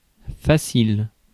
Ääntäminen
IPA: /fa.sil/